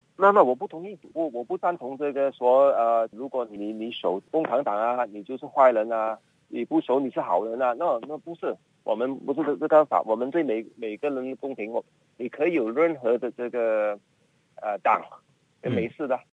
接受SBS 普通话电台采访时说。